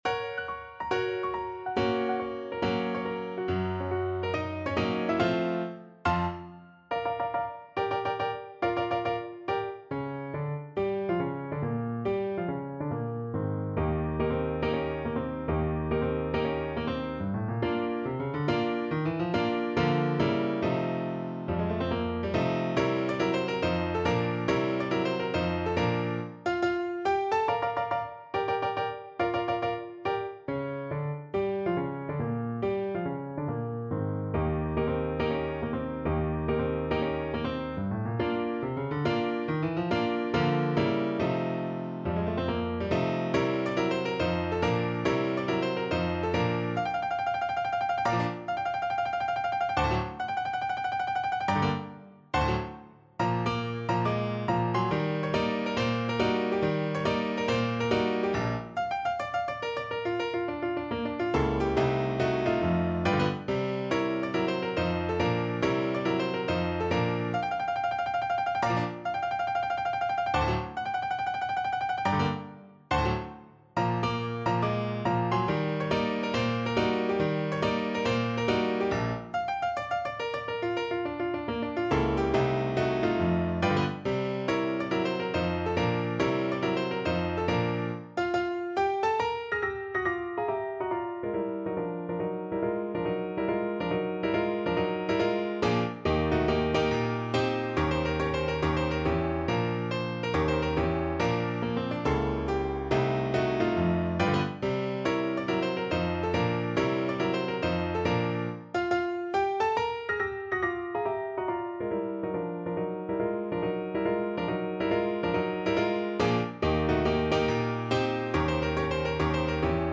No parts available for this pieces as it is for solo piano.
Bb major (Sounding Pitch) (View more Bb major Music for Piano )
Tempo di Blues = 140
4/4 (View more 4/4 Music)
Piano  (View more Advanced Piano Music)
Jazz (View more Jazz Piano Music)